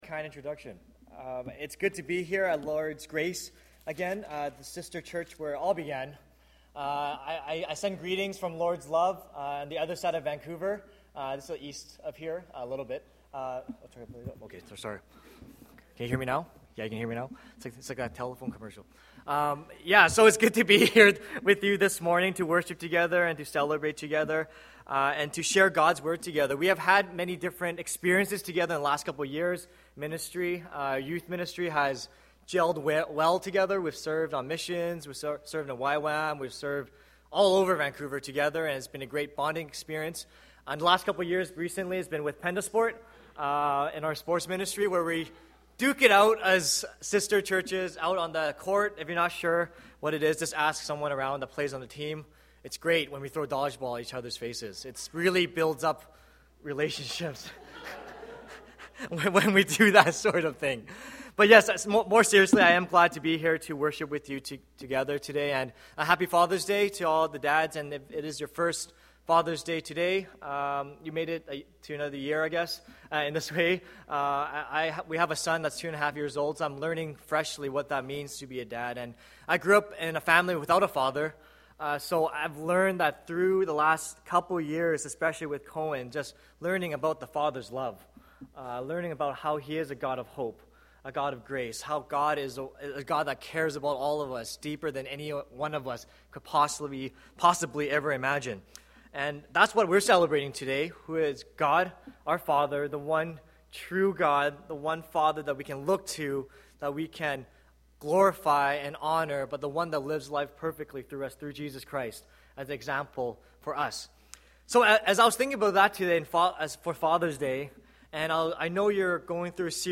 Sermons | Lord's Grace Church